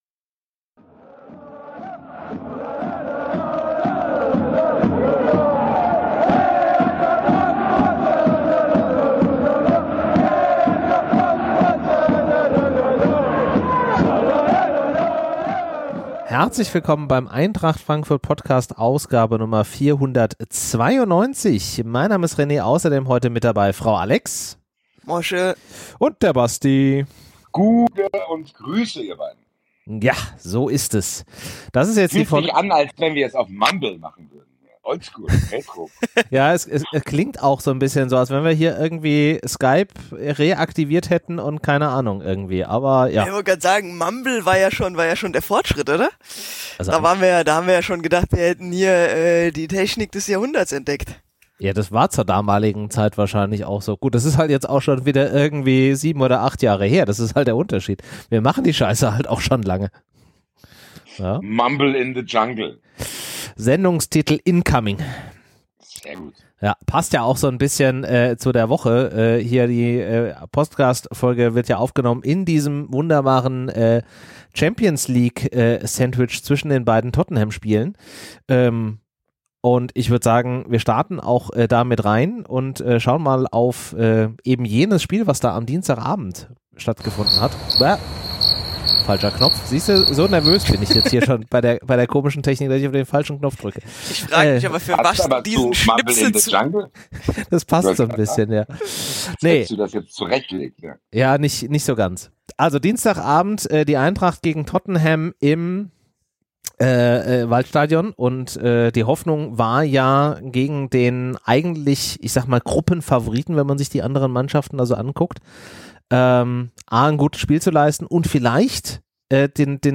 Leichtes 80s Feeling bei der Aufnahme